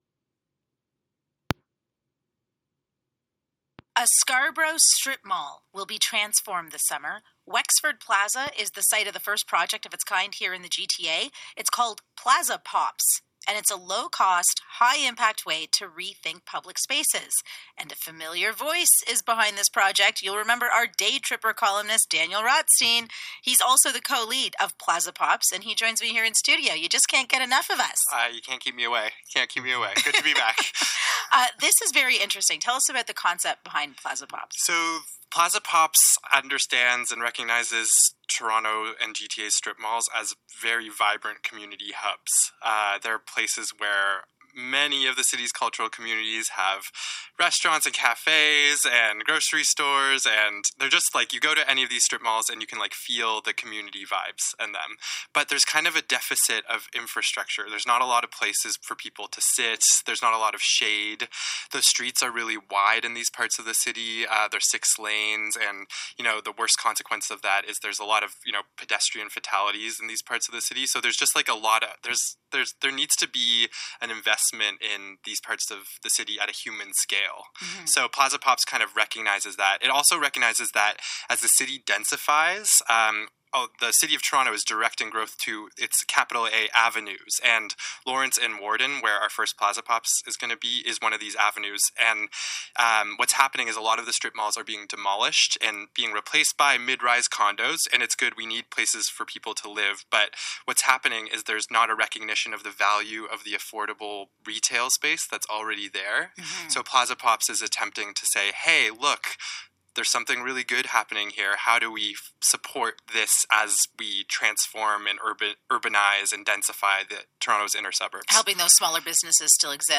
That day, CBC Radio was broadcasting out of the Scarborough Town Centre mall, as part of wider coverage and focus on projects happening in Scarborough!